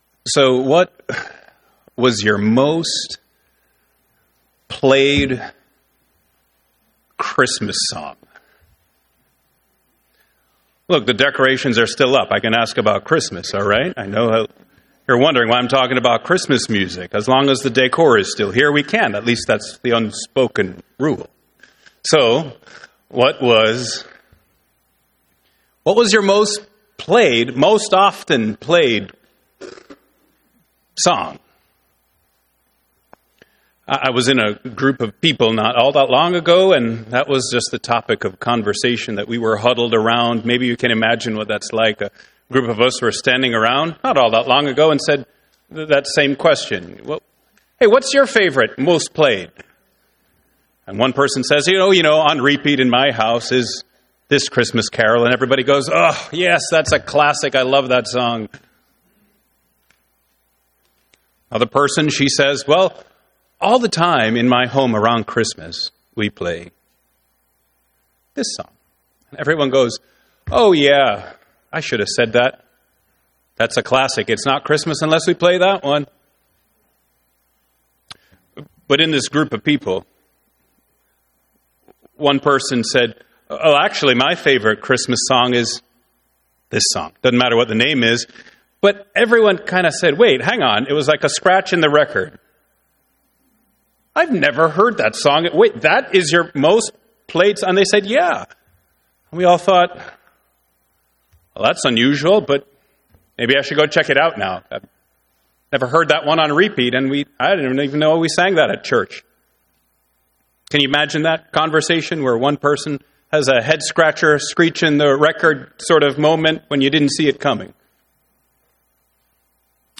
Sermon Outline 1.